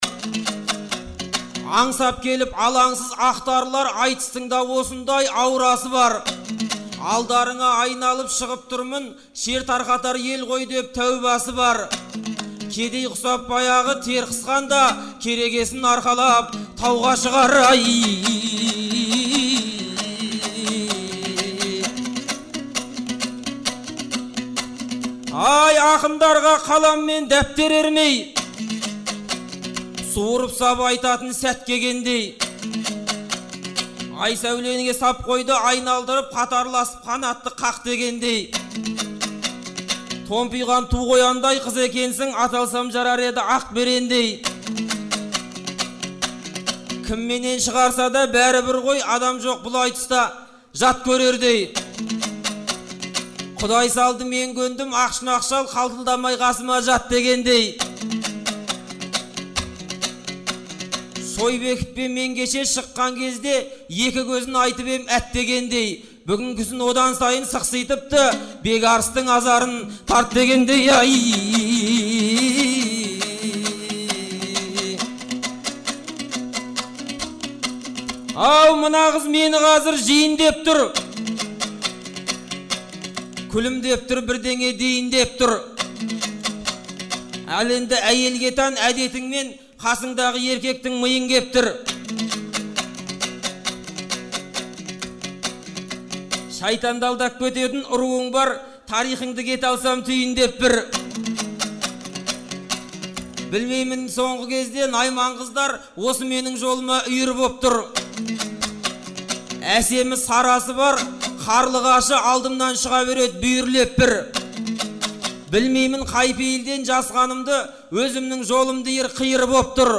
Наурыздың 15-і мен 16-сы күні Шымкент қаласында «Наурыз айтысы» өтті.
Сондықтан тек наурыздың 16-сы күні өткен айтысты оқырмандар назарына ұсынып отырмыз.